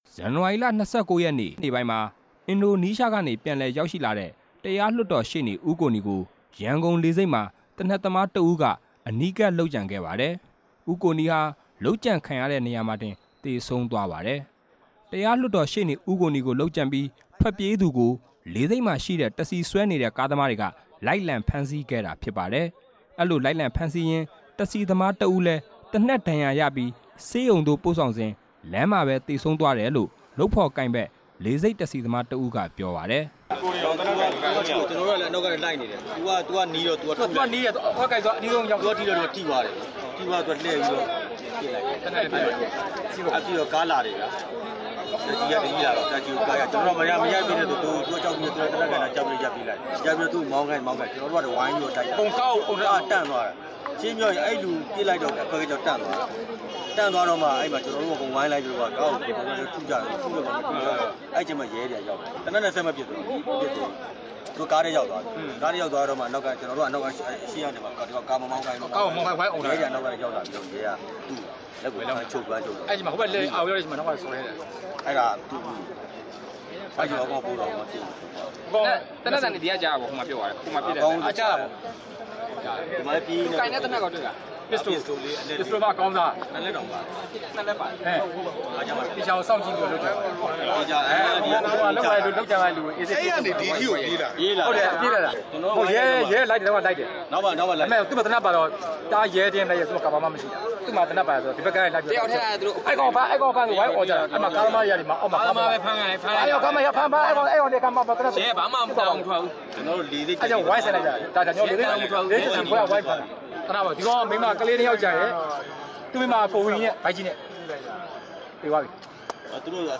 ရှေ့နေ ဦးကိုနီ လုပ်ကြံခံရမှု မျက်မြင်သက်သေတွေကို မေးမြန်းတင်ပြချက်